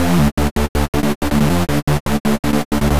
Index of /musicradar/future-rave-samples/160bpm